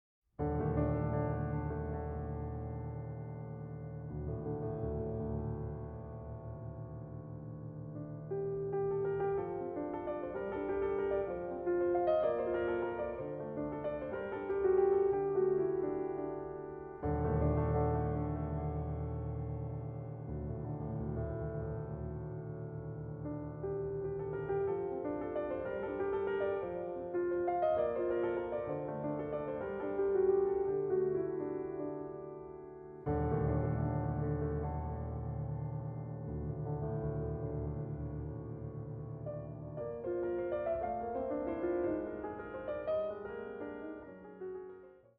これらの「聴きやすい」曲にはクライマックスや終着点が無く、宙に浮くように美しい叙情性だけがいつまでも残っていきます。